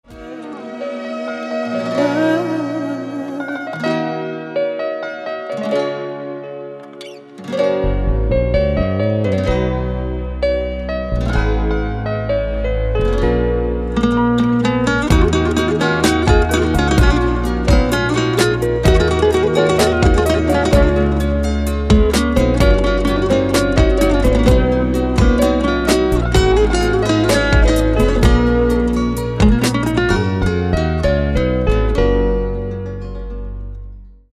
جلوه های صوتی
بی کلام